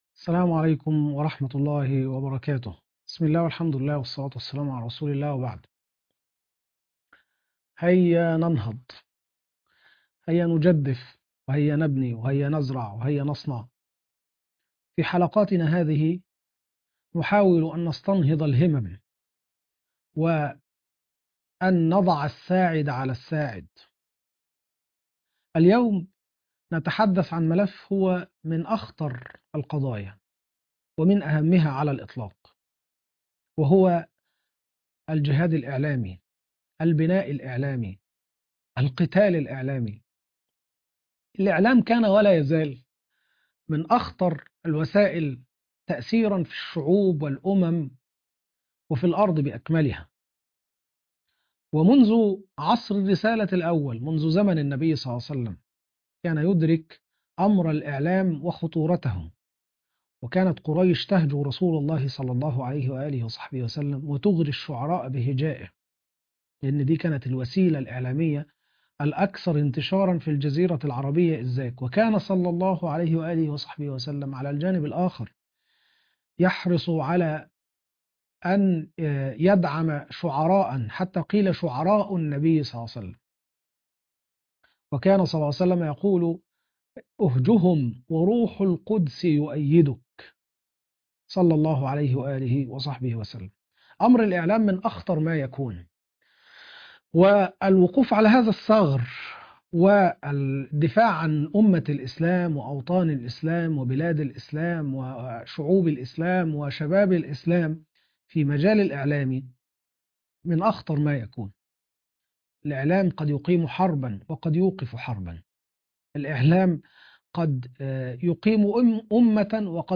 المقرأة - سورة مريم - طه ص 312